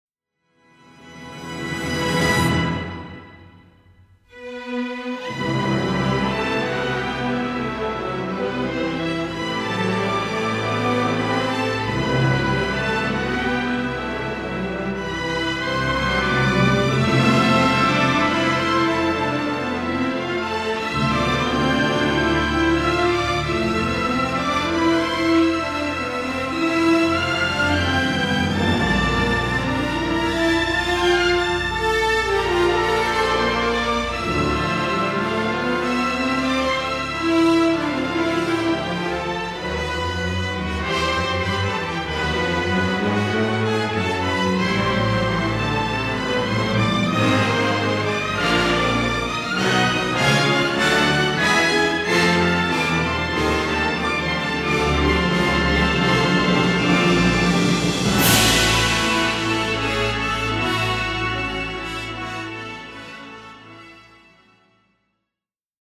unabashedly romantic
suspenseful and brooding, pastoral and uplifting